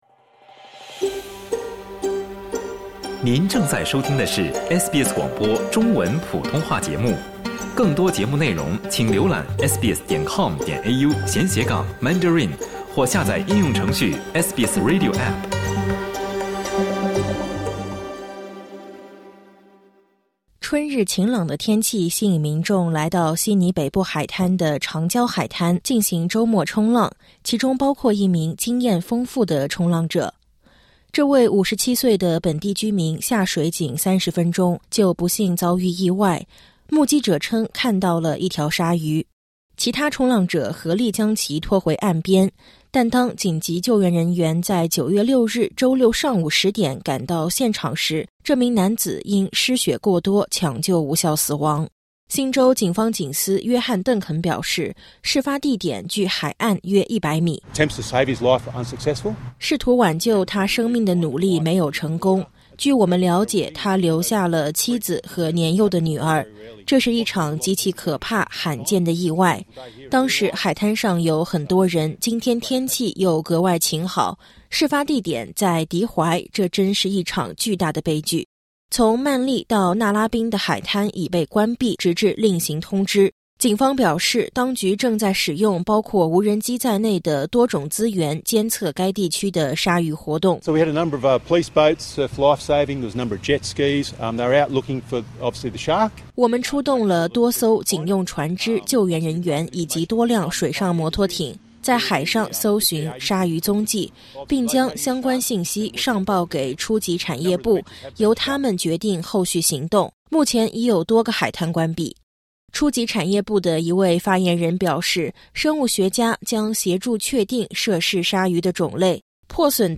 在悉尼北部海滩发生一起致命鲨鱼袭击事件后，当局正出动无人机监测鲨鱼活动。相关海滩将继续关闭，当局正在与生物学家合作，以确定涉事鲨鱼的种类。点击音频，收听完整报道。